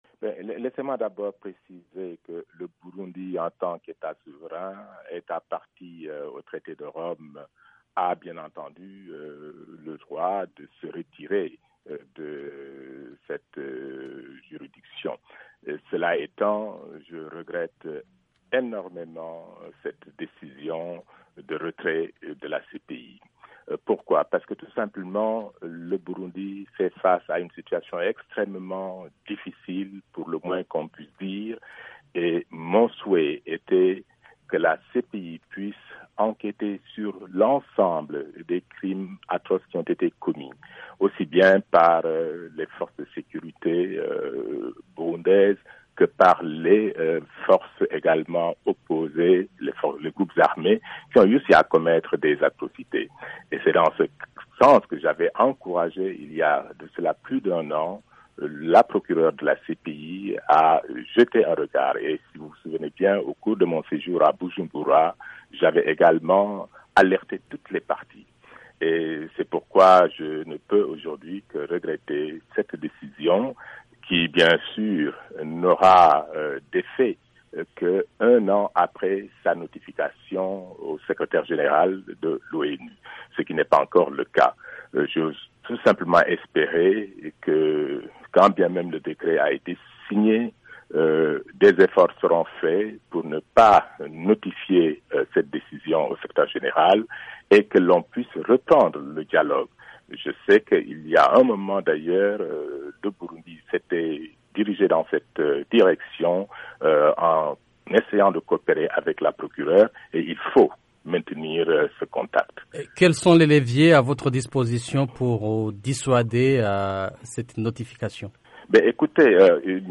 Adama Dieng, Conseiller spécial des Nations Unies sur la prévention du génocide.
Le retrait du Burundi de la Cour Pénale Internationale (CPI) ne va pas interrompre le travail de la Procureur déjà entamé sur les crimes commis dans le pays depuis avril 2015, déclare le conseiller spécial des Nations Unies sur la prévention du génocide, Adama Dieng, dans une interview avec VOA Afrique.